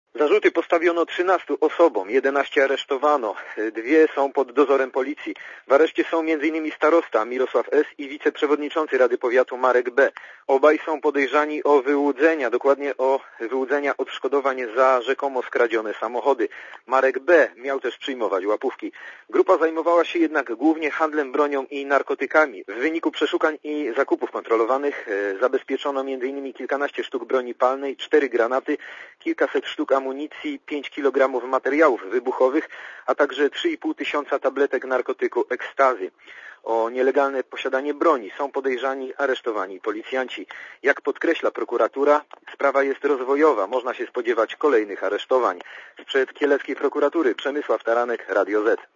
Posłuchaj relacji korespondenta Radia Zet (190 KB)